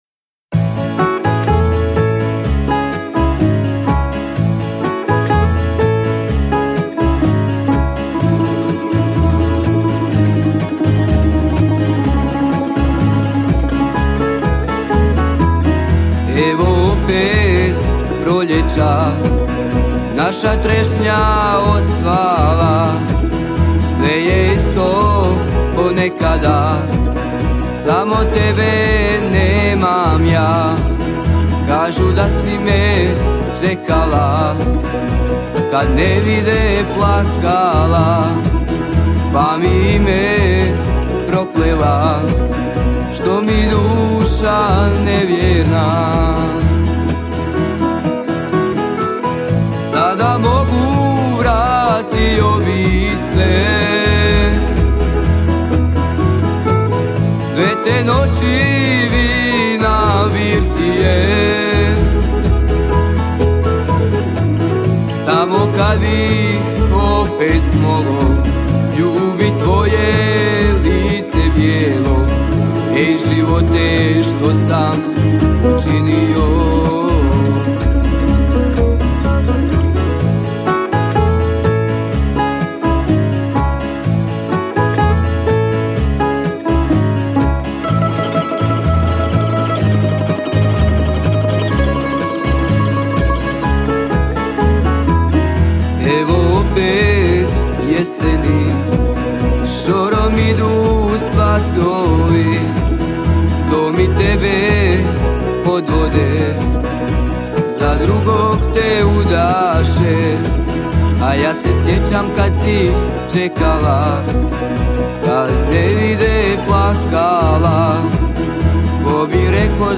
Festival pjevača amatera
Zvuci tamburice odzvanjali su prepunom dvoranom vatrogasnog doma u Kaptolu do kasnih noćnih sati.